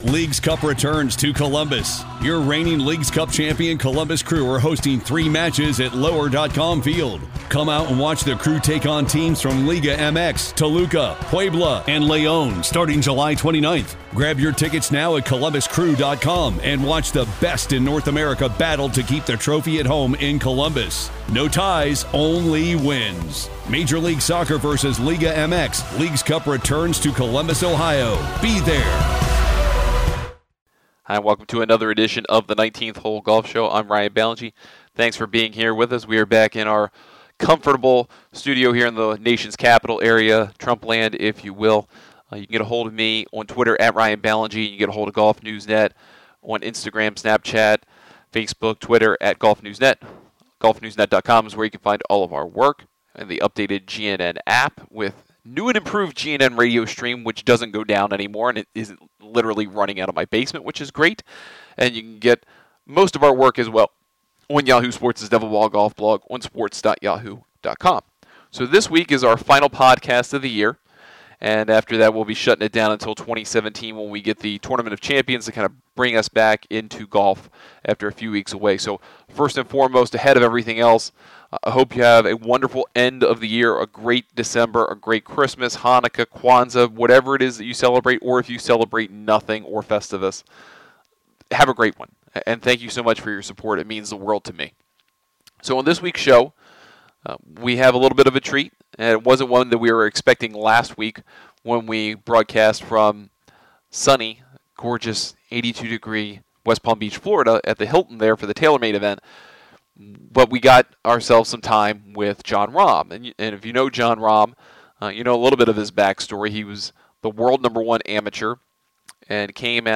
We spoke with Rahm at TaylorMade Golf's 2017 line reveal about his crazy early run as a pro, his equipment, his goals for next year and some of the people who have helped him get to this point. Then we close the show and the year with some news items, including Phil Mickelson's second sports hernia surgery, the birth of the Senior LPGA Championship and Tiger Woods committing to the 2017 Genesis Open.